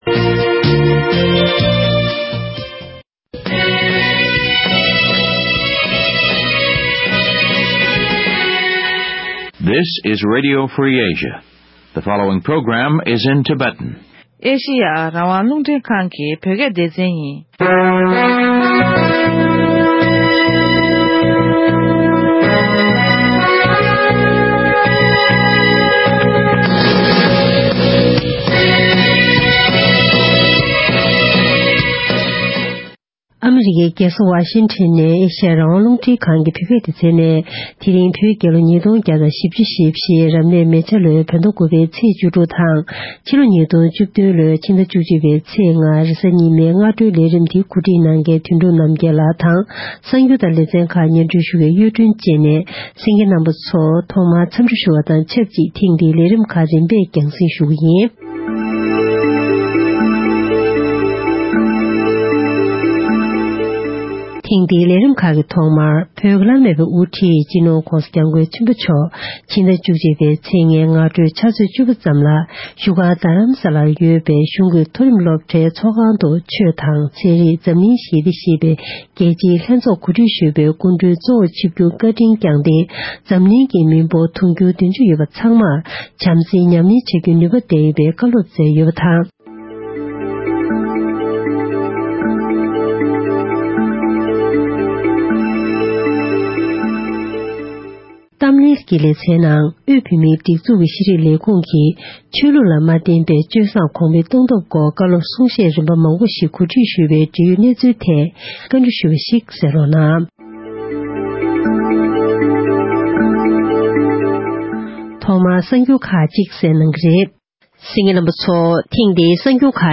གནས་འདྲི་ཞུས་པ་ཁག་གཤམ་ལ་གསན་རོགས་གནང༌༎